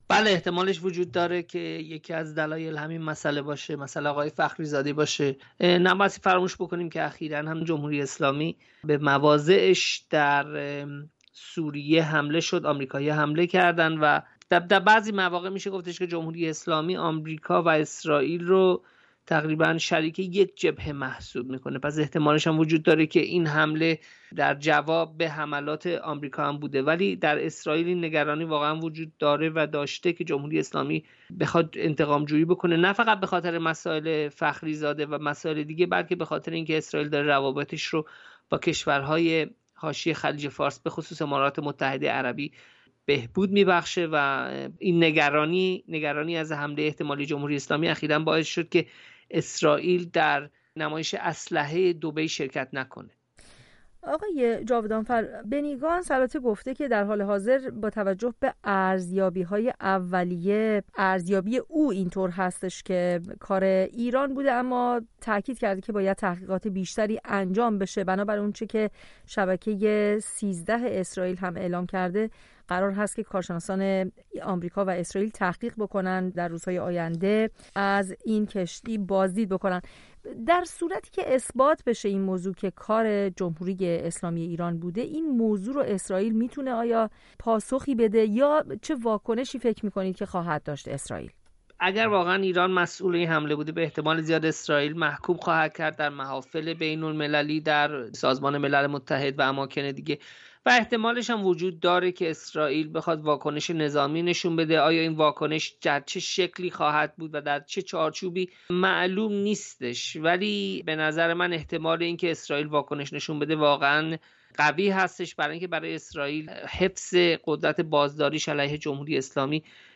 در گفت و گو